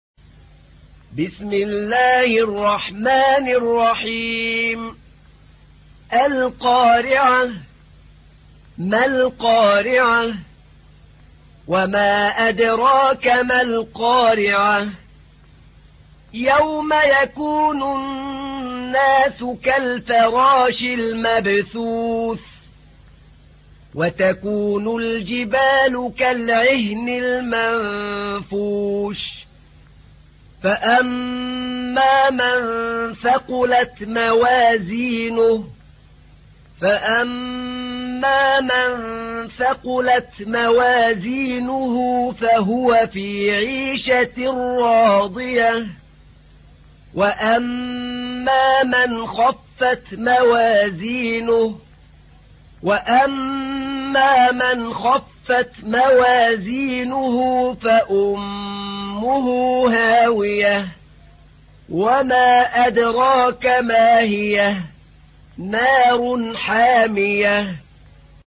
تحميل : 101. سورة القارعة / القارئ أحمد نعينع / القرآن الكريم / موقع يا حسين